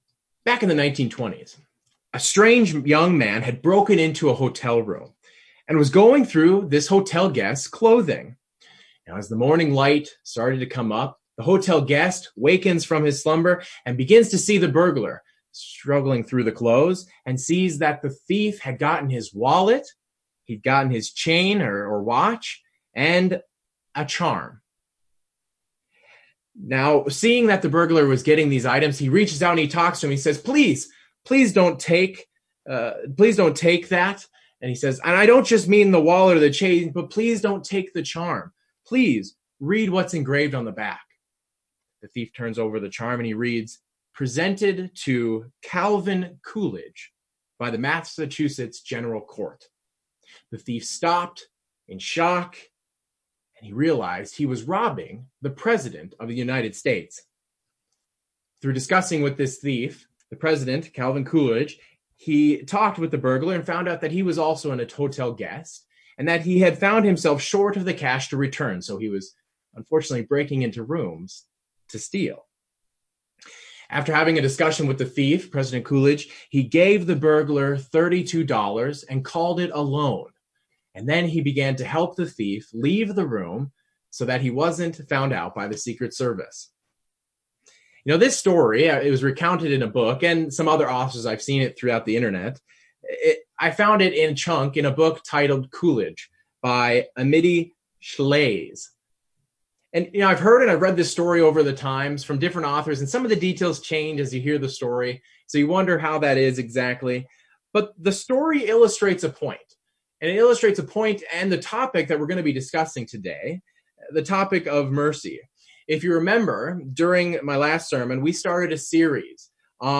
The Lord requires of His people to do justly, love mercy, and walk humbly with Him. In this second part in a series on the Lord's requirement, the sermon will examine the statement to love mercy.